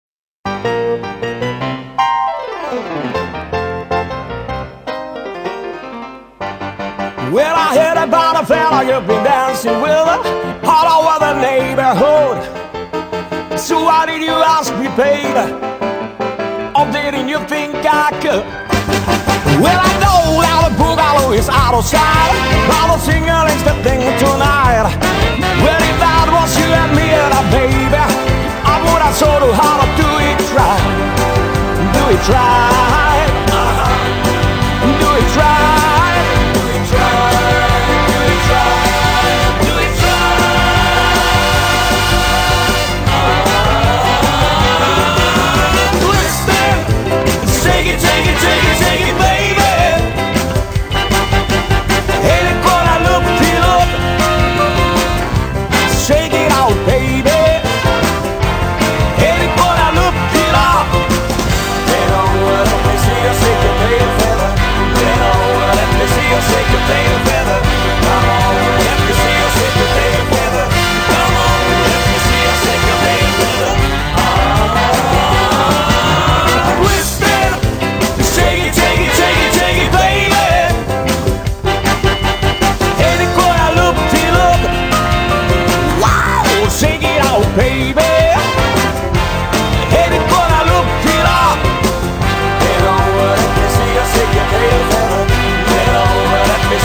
BLUES